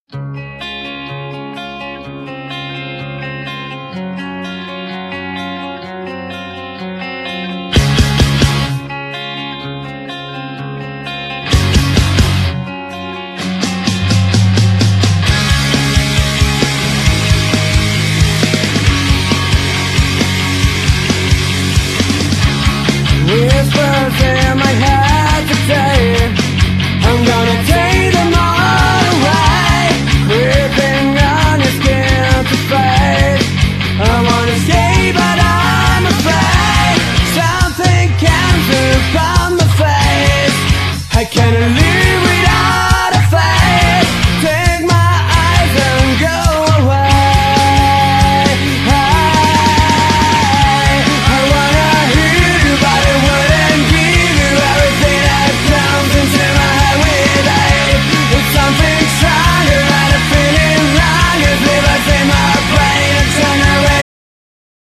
Genere : Punk HC